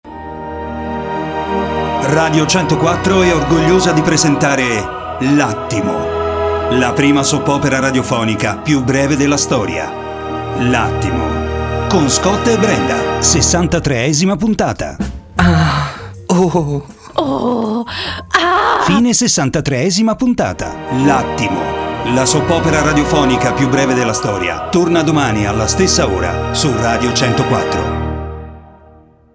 L’Attimo – la Soap opera più breve della storia. Sessantatreesima puntata